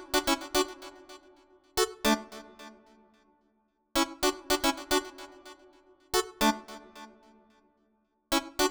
35 Synth PT2.wav